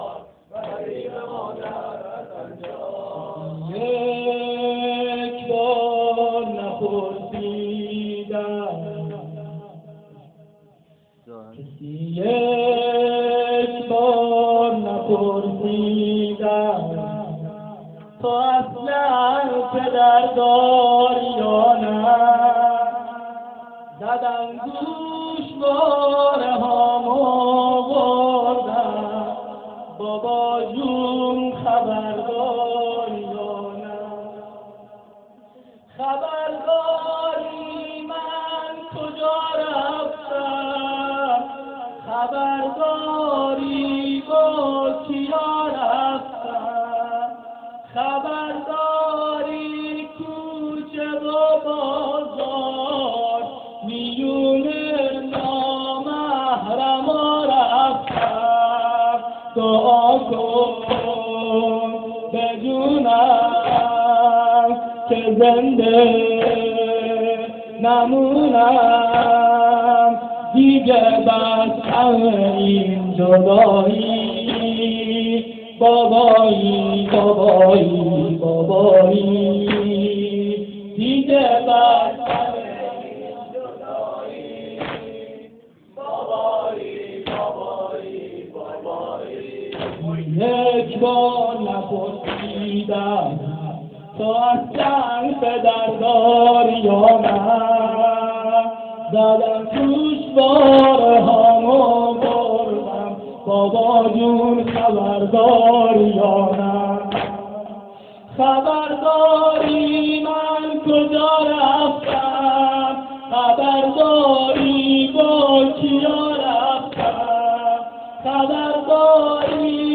جلسه هفتگی 18صفر حوزه علمیه امام صادق{ع}زابل باکیفیت پائین پائین